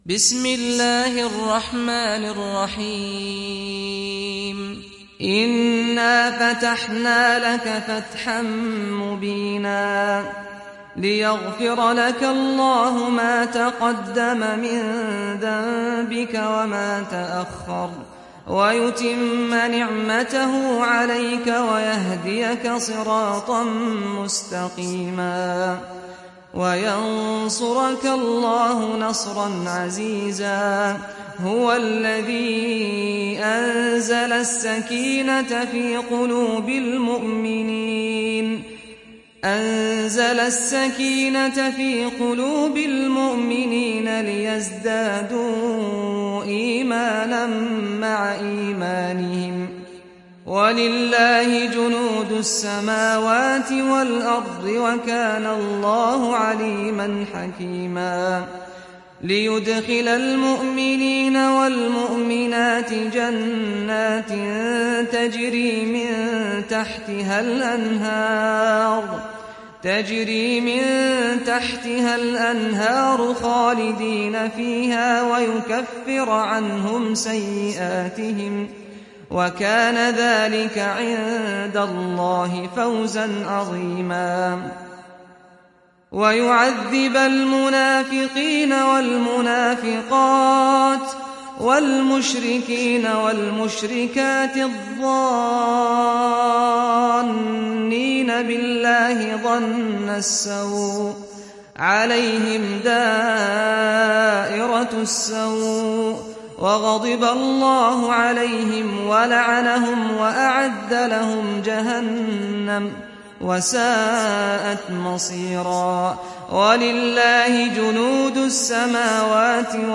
دانلود سوره الفتح mp3 سعد الغامدي روایت حفص از عاصم, قرآن را دانلود کنید و گوش کن mp3 ، لینک مستقیم کامل